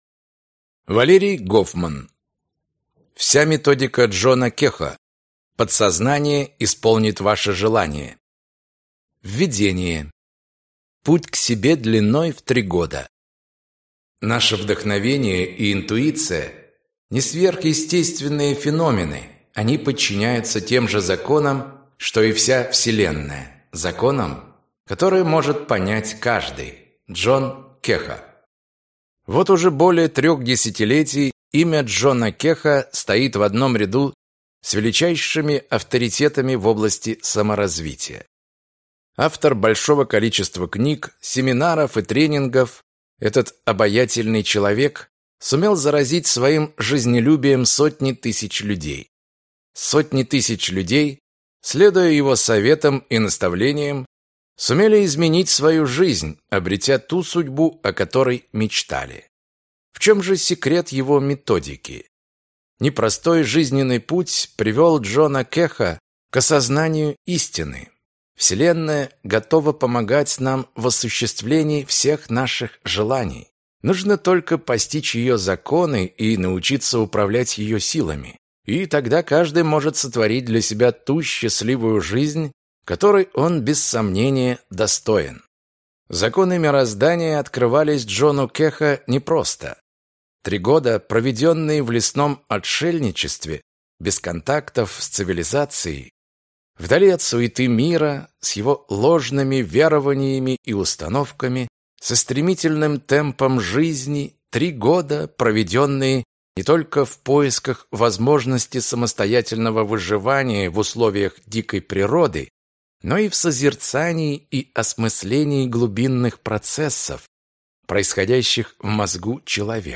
Аудиокнига Вся методика Джона Кехо. Подсознание исполнит ваше желание!